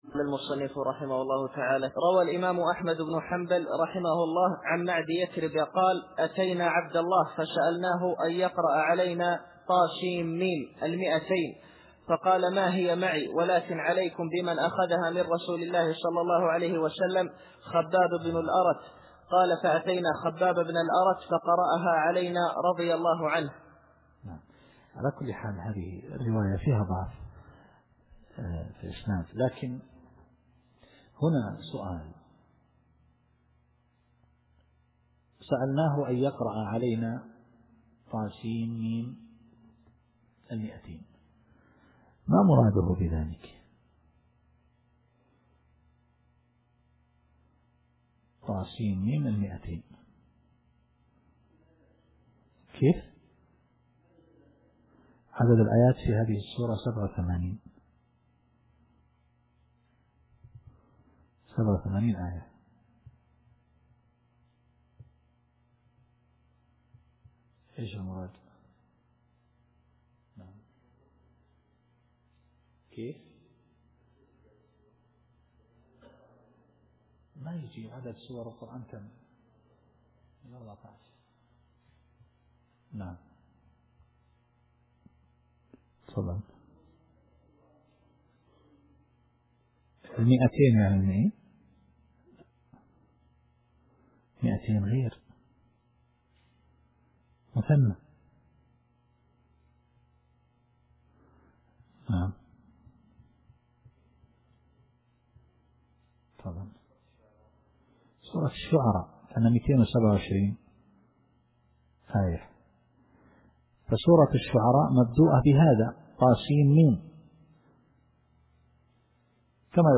التفسير الصوتي [القصص / 1]